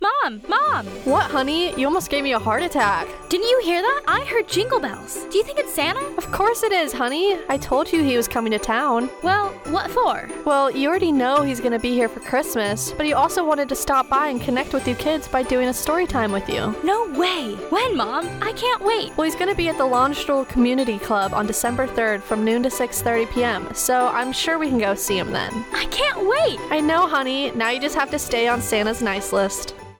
This is a 30 second radio spot covering "Storytime with Santa" hosted by Army Community Service on Dec. 3, 2024, Kaiserslautern, Germany.